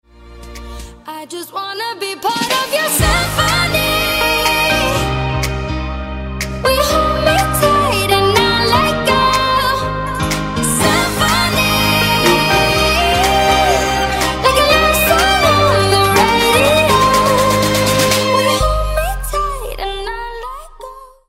Symphony